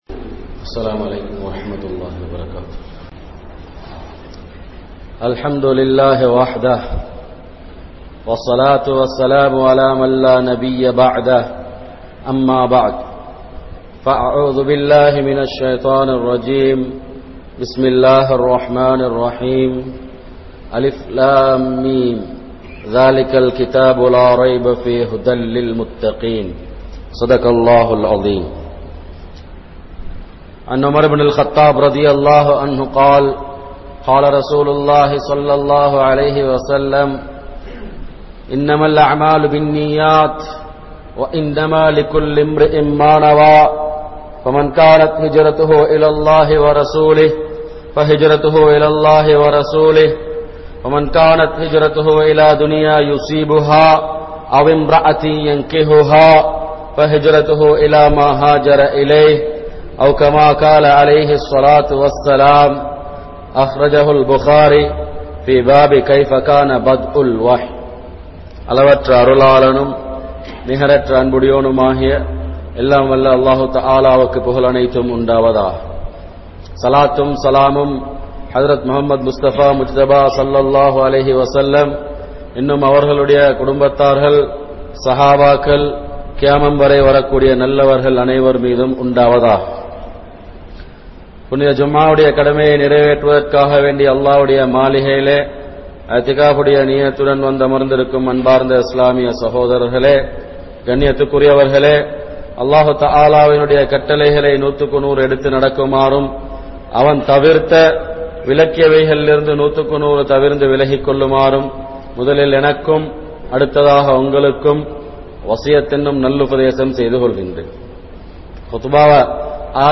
Ulahaththukkaaha Vaalum Manitharhal (உலகத்துக்காக வாழும் மனிதர்கள்) | Audio Bayans | All Ceylon Muslim Youth Community | Addalaichenai
Jamiul Khairath Jumua Masjith